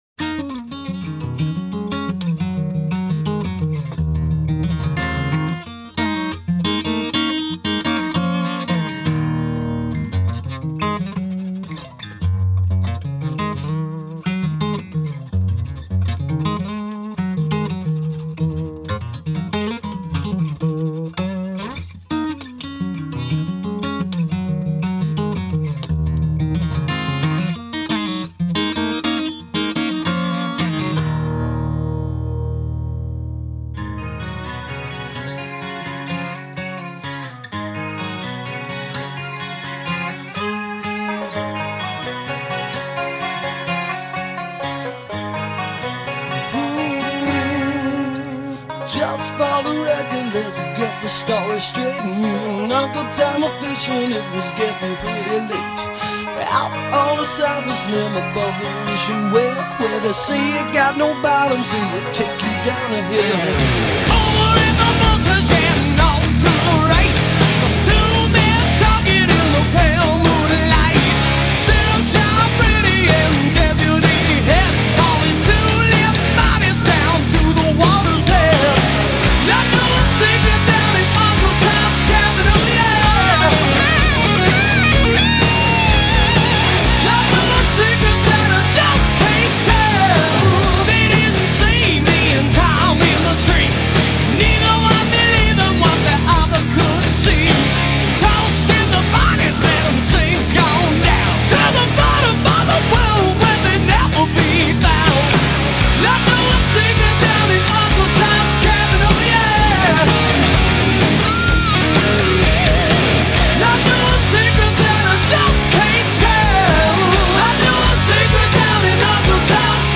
Glam Metal, Hard Rock